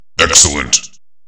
Quake_3_Excellent_Sound_Effect.mp3